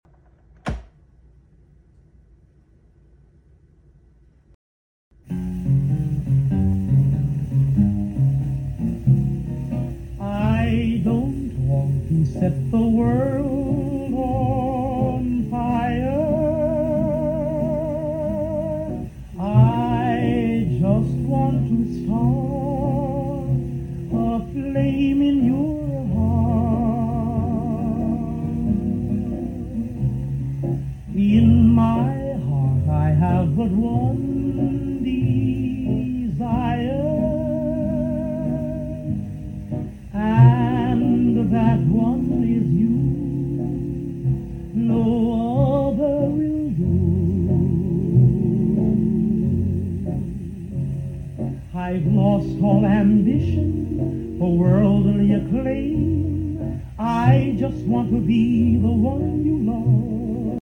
Soundtrack vinyl